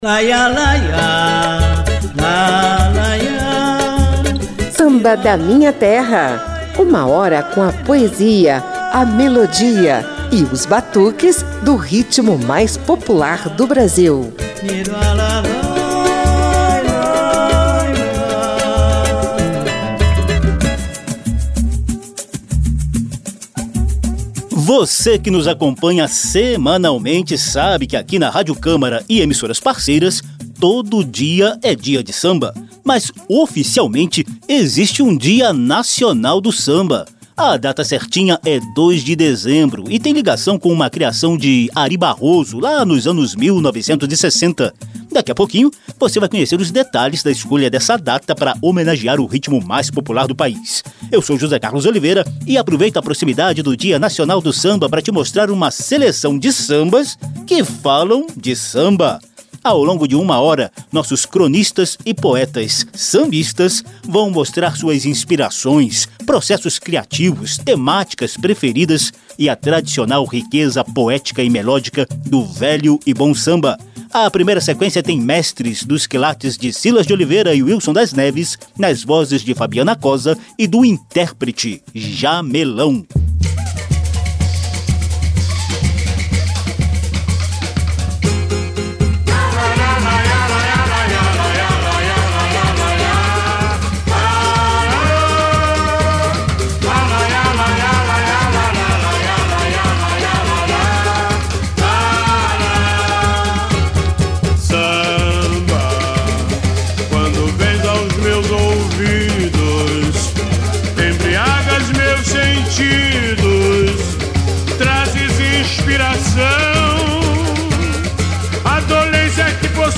O programa também traz sequência de sambas forjados no sofrido cotidiano brasileiro.